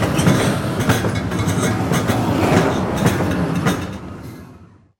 Train Passing
A train passing by at speed with Doppler shift, rail clatter, and horn in the distance
train-passing.mp3